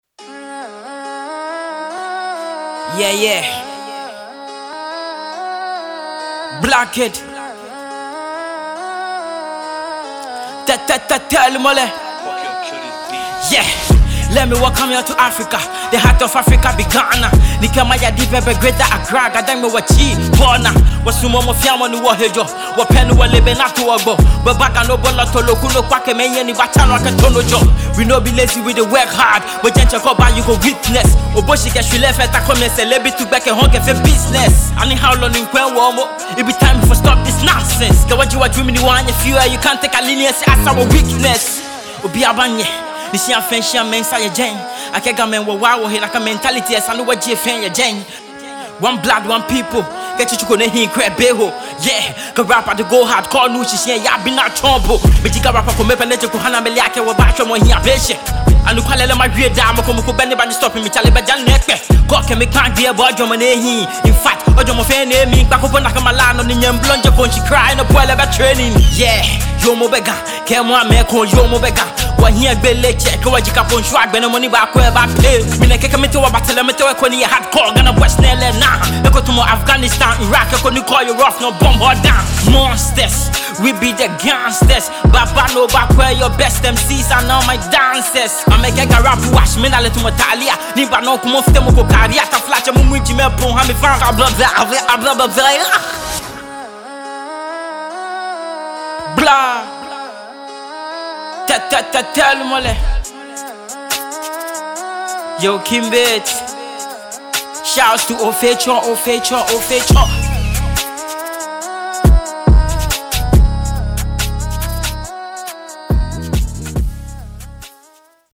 Mixed and mastered